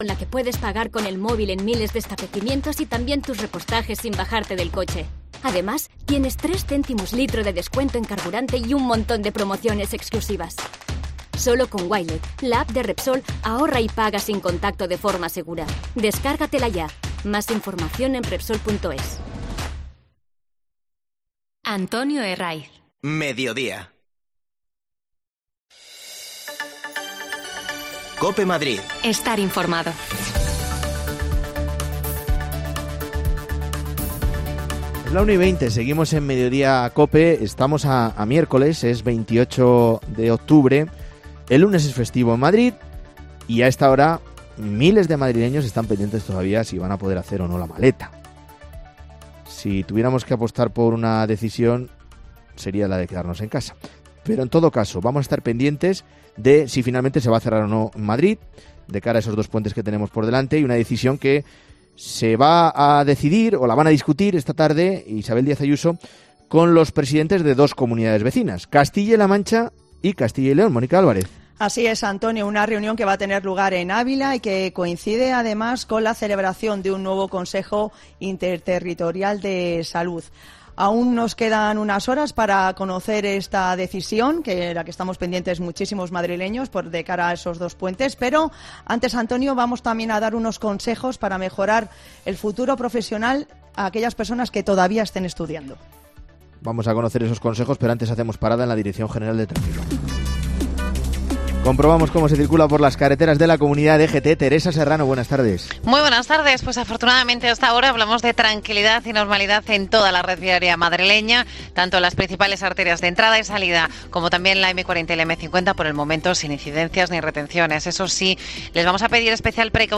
Te contamos las últimas noticias de la Comunidad de Madrid con los mejores reportajes que más te interesan y las mejores entrevistas , siempre pensando en el ciudadano madrileño.